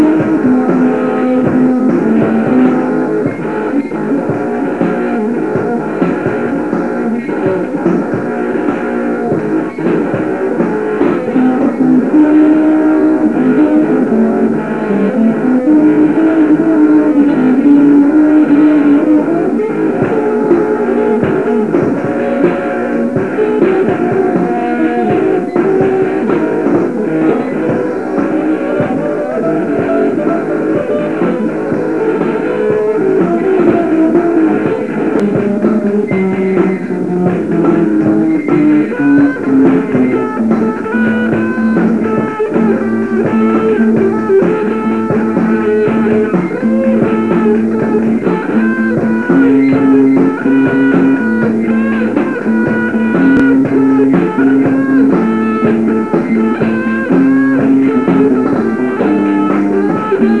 Ladossa
HWLive.wav